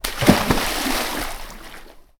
animalworld_shark.ogg